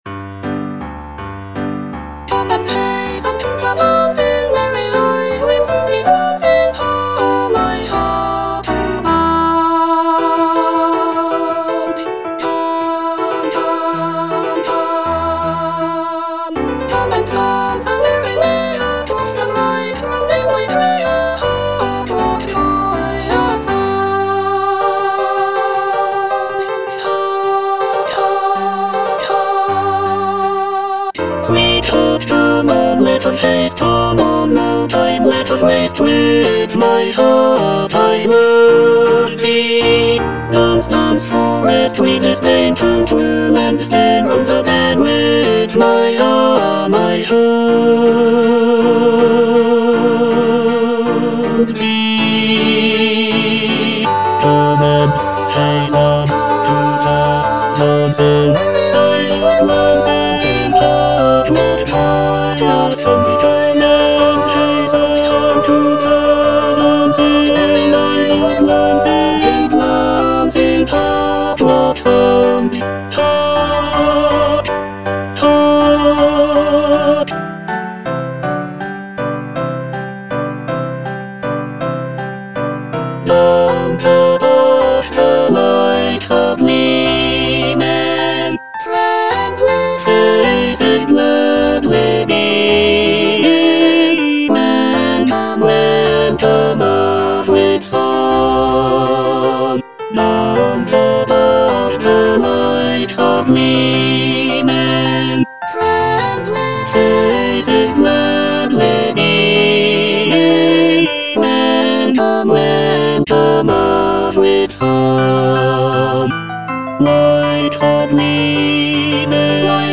E. ELGAR - FROM THE BAVARIAN HIGHLANDS The dance (tenor II) (Emphasised voice and other voices) Ads stop: auto-stop